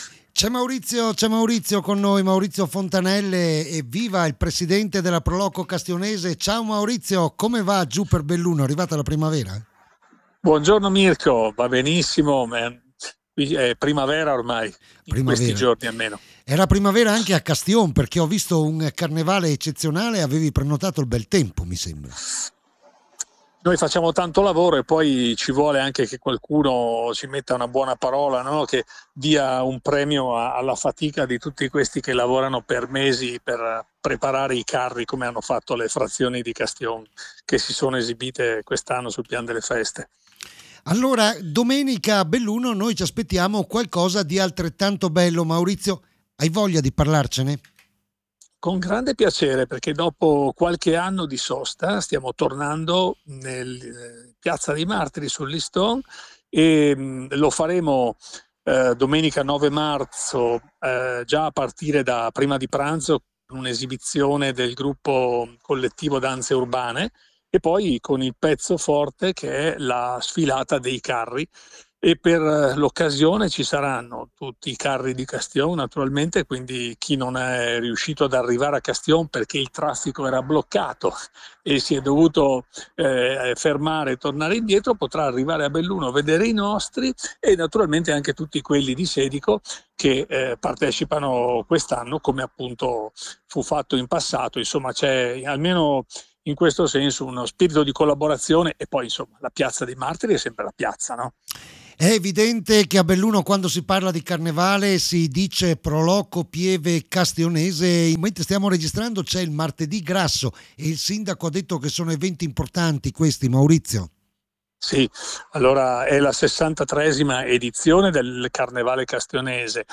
L’INTERVISTA ALLA RADIO